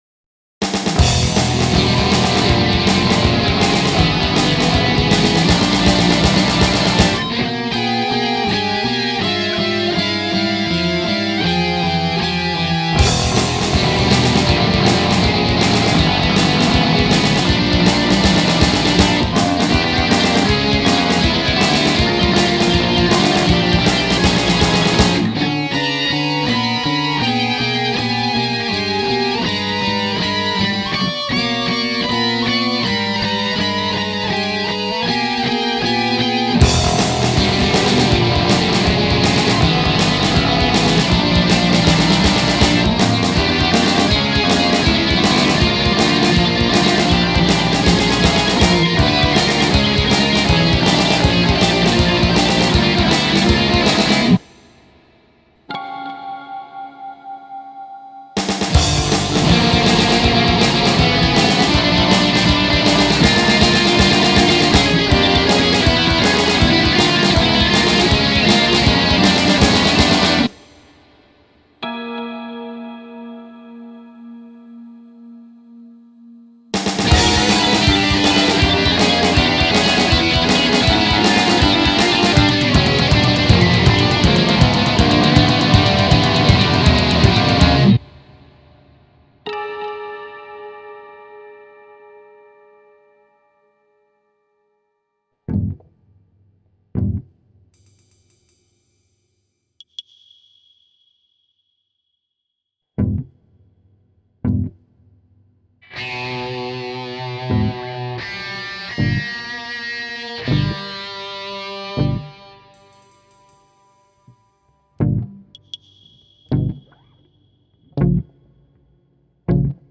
Boss digital recording studio BR-1600
Boss Dr. Rhythm DR-3
sE Electronics condensatormicrofoons SE4A (matched pair)
Ibanez SR 300 FM basgitaar
Speelgoedinstrumentjes